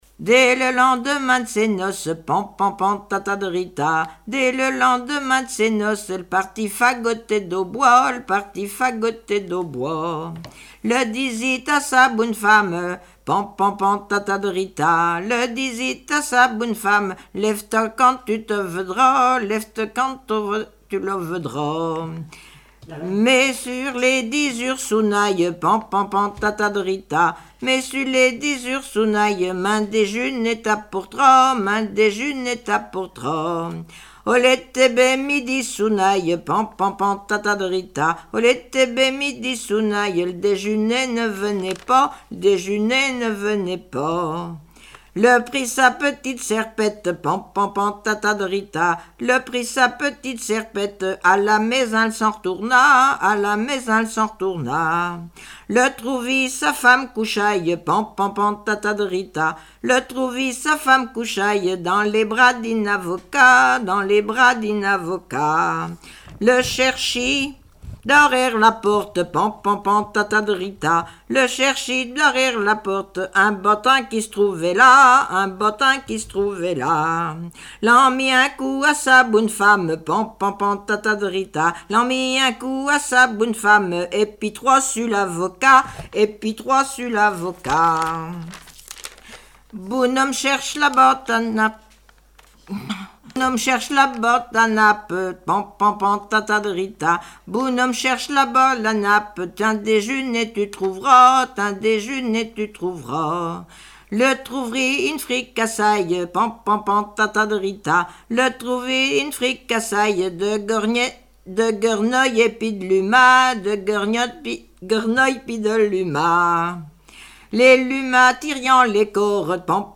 Mémoires et Patrimoines vivants - RaddO est une base de données d'archives iconographiques et sonores.
Genre laisse
Catégorie Pièce musicale inédite